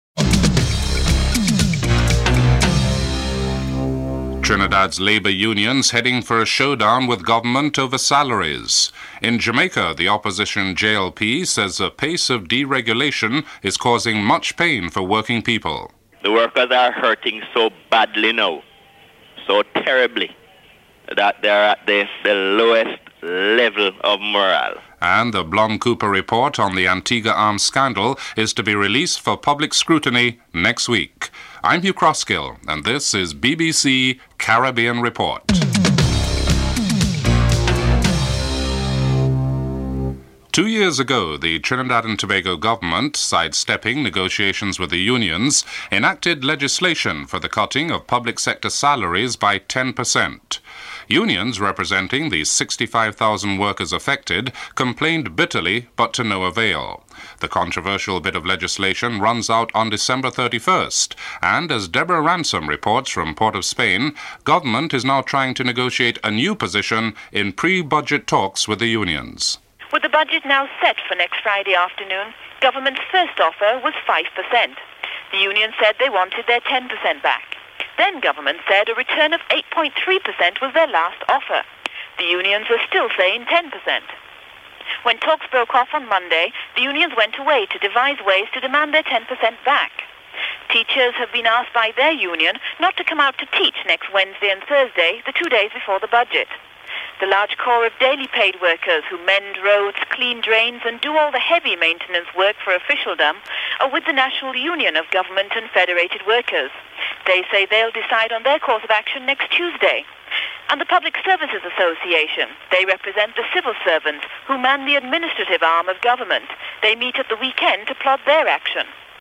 1. Headlines (00:00-00:39)
Interviews with Errol Ennis, Minister of State in the Ministry of Finance and Senator Dwight Nelson of the Opposition Labour Party (05:12-11:00)